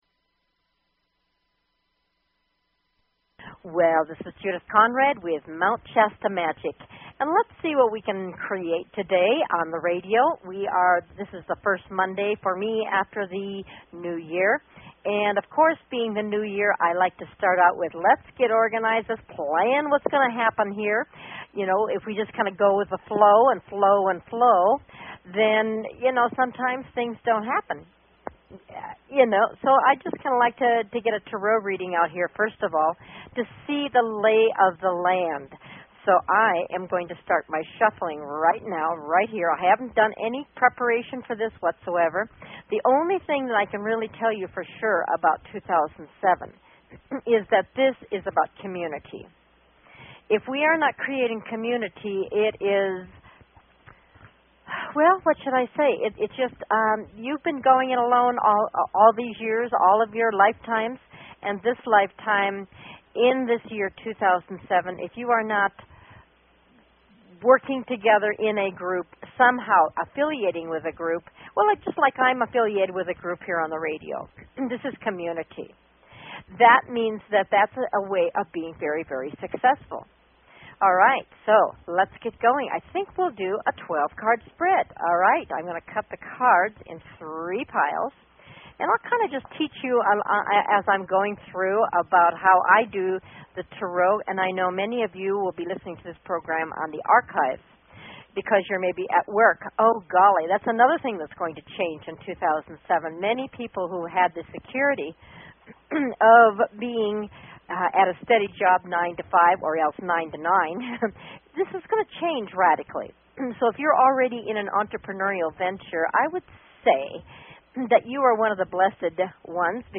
Talk Show Episode, Audio Podcast, Mount_Shasta_Magic and Courtesy of BBS Radio on , show guests , about , categorized as